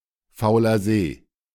Fauler See (German pronunciation: [ˈfaʊ̯lɐ zeː]